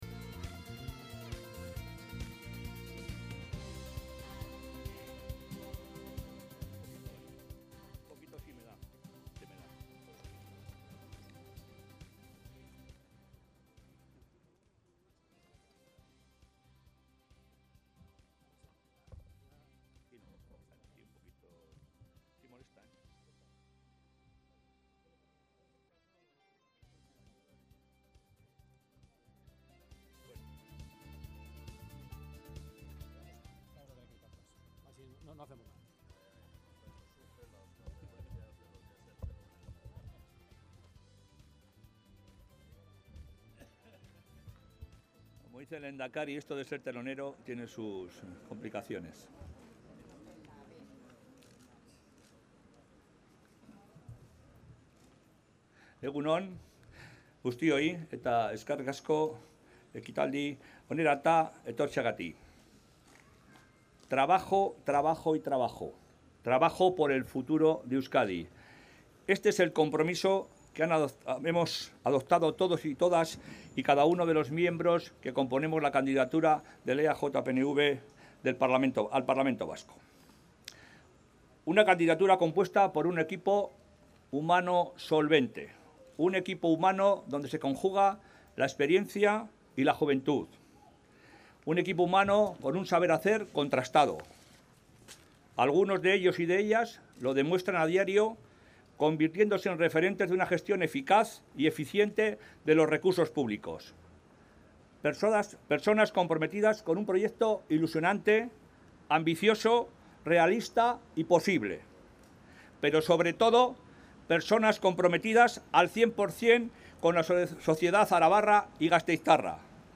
Euskal Hauteskundeetarako Arabako EAJ-PNVren hautagaien aurkezpena